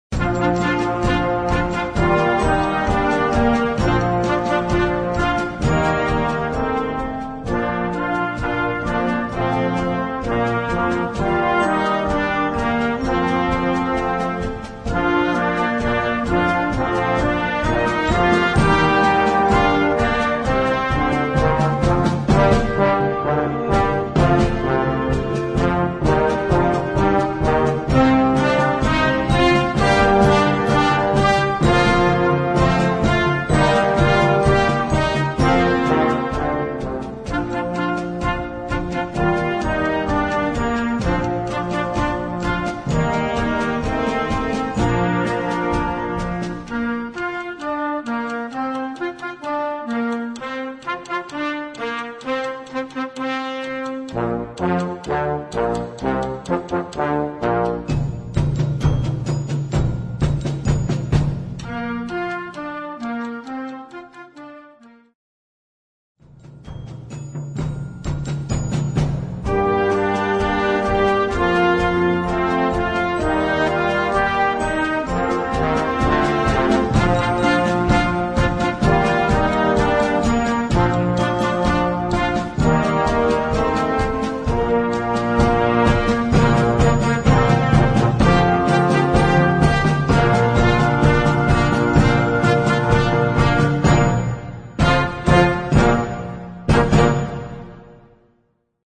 Partitions pour orchestre d'harmonie des jeunes.